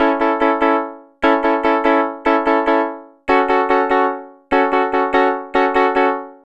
Twisting 2Nite 1 Piano-A.wav